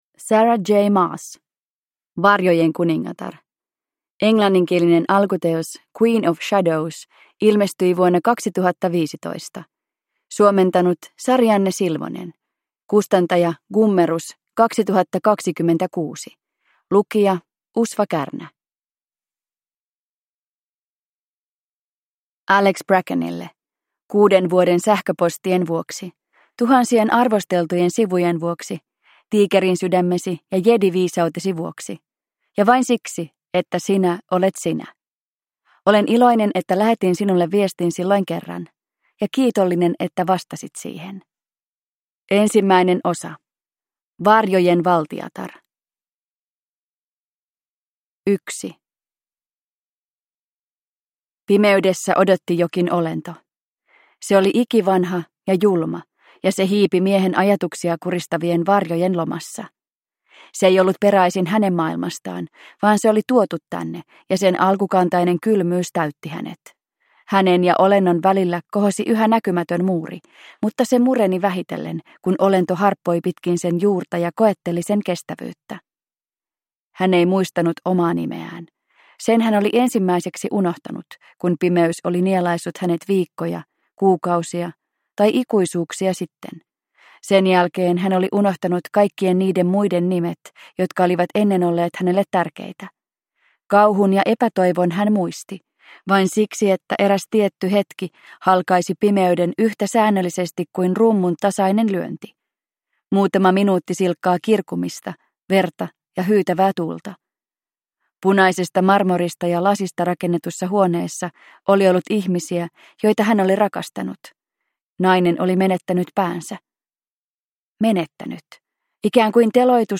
Throne of Glass - Varjojen kuningatar – Ljudbok